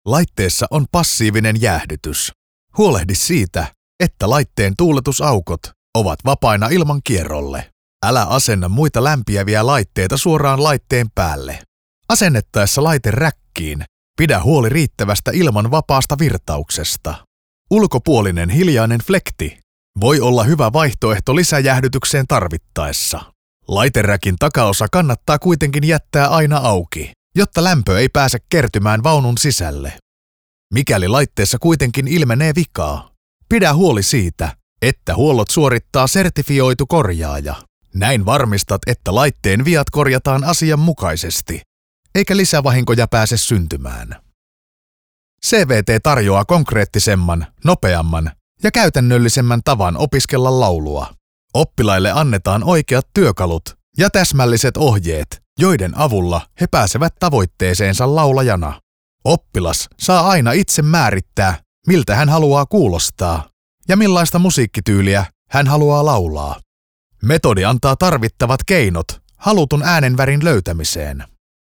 Native finnish professional voiceover artist with a warm versatile voice for all kind of voiceover work.
Sprechprobe: eLearning (Muttersprache):
I record using a very highend recording gear and signal chain.